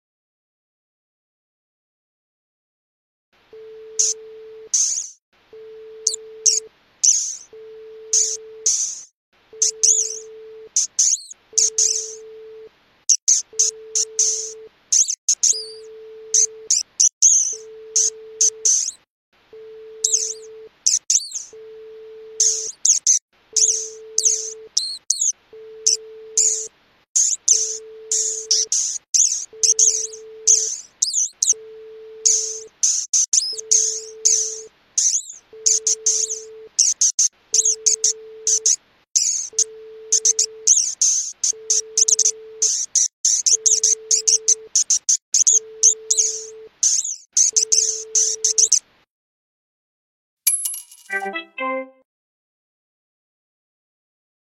効果音 ねずみ 着信音